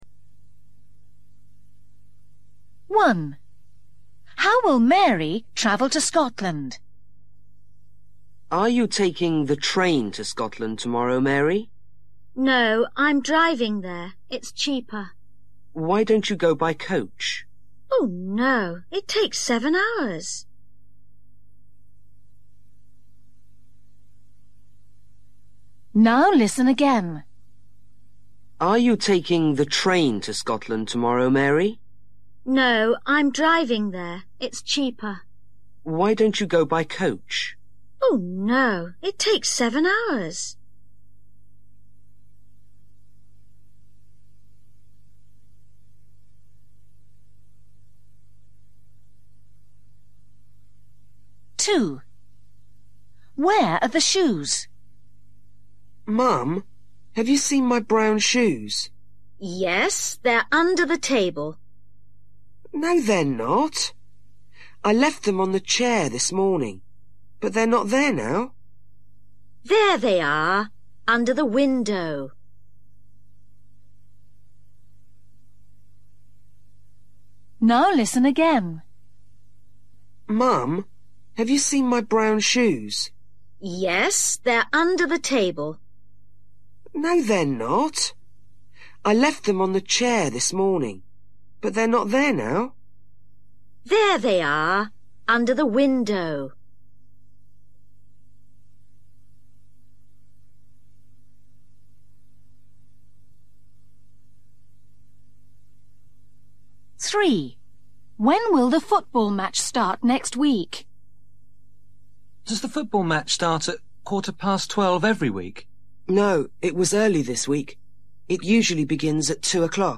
Listening: Conversations at home or in a shop